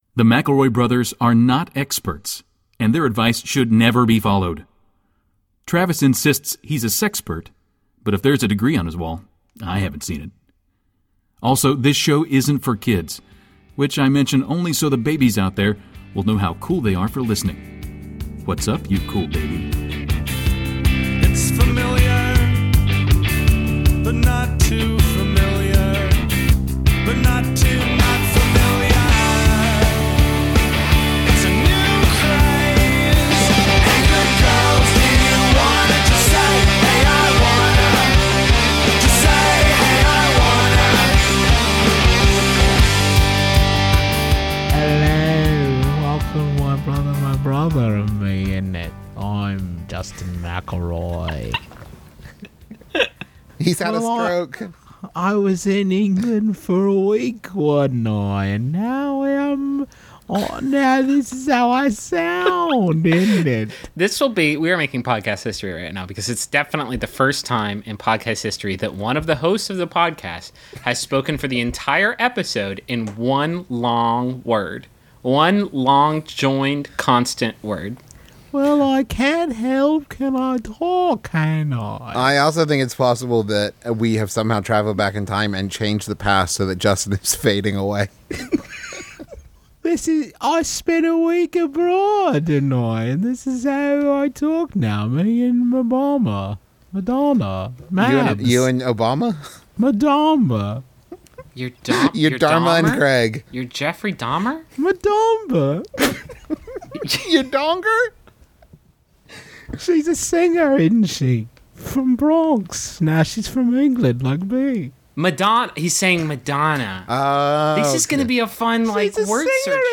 Mbmbam, Mcelroy Brothers, Advice, Justin Mcelroy, Travis Mcelroy, Comedy Advice, Mcelroy, Griffin Mcelroy, Comedy